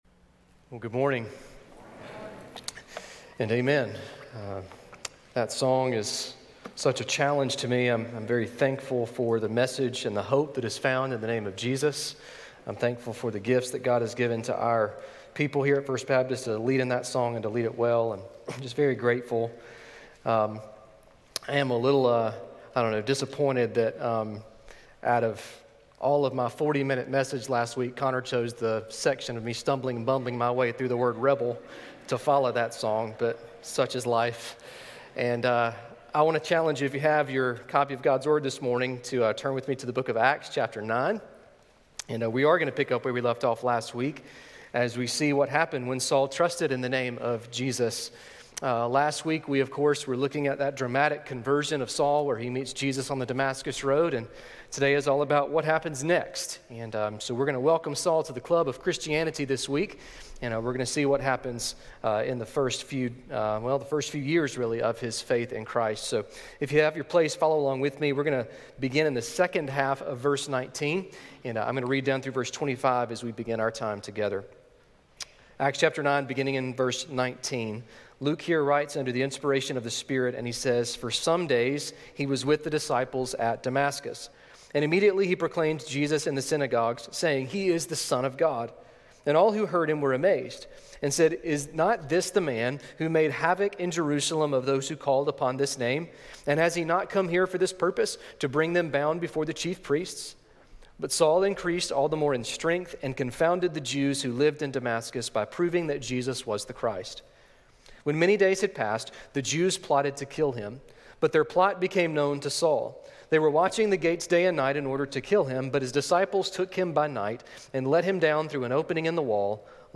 Sermon Listen Service Scripture References